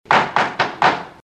Original creative-commons licensed sounds for DJ's and music producers, recorded with high quality studio microphones.
GAVEL BANGING.wav
[Pounding-Gavel]-These-are-the-charges_ePm.wav